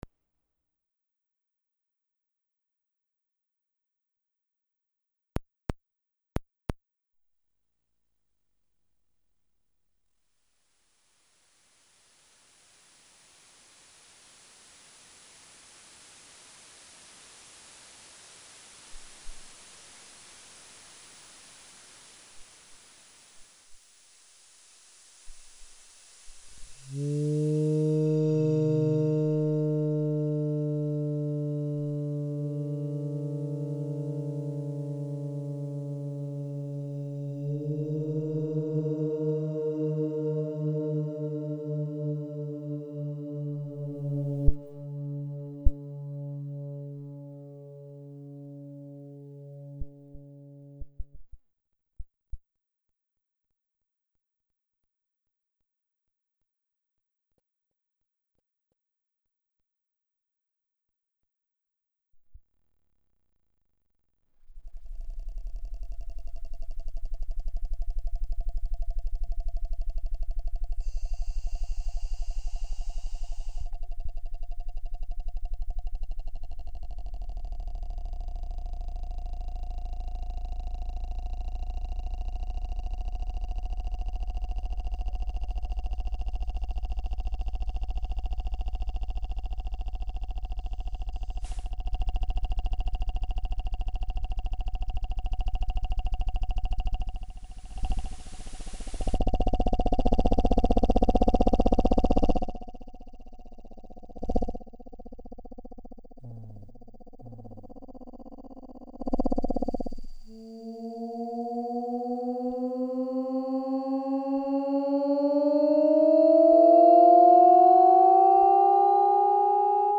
violin
viola
clarinet
trumpet
trombone
electronics